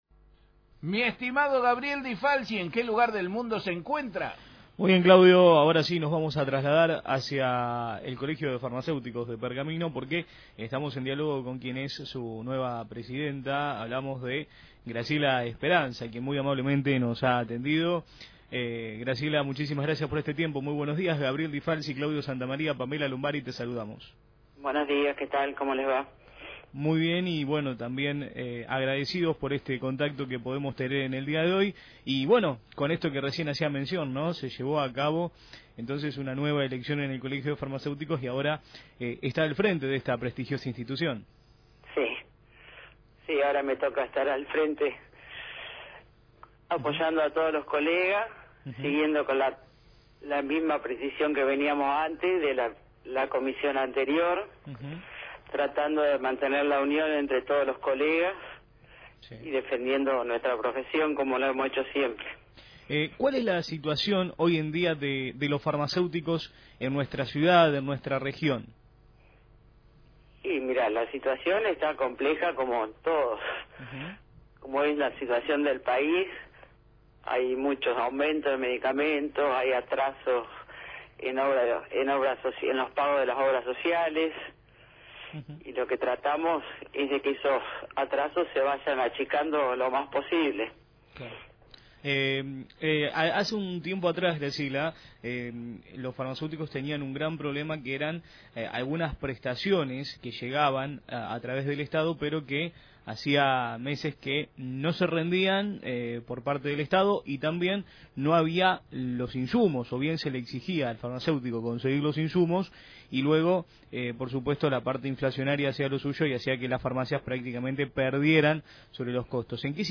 En dialogo con «La Mañana de la Radio»